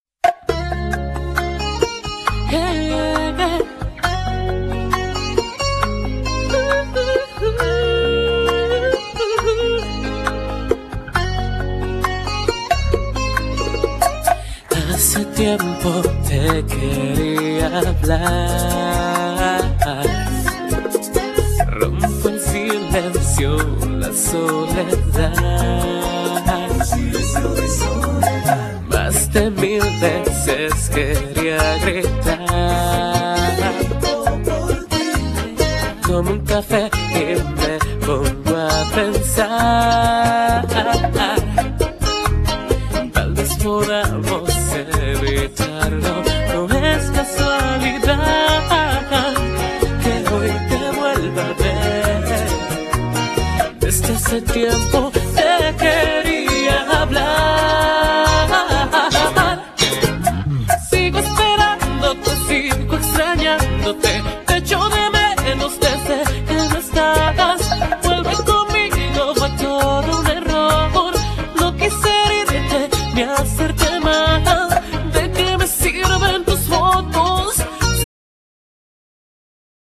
Genere : Pop latin